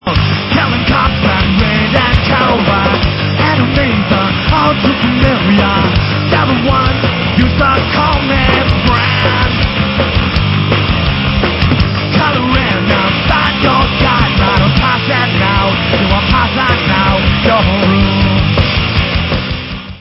sledovat novinky v oddělení Rock/Punk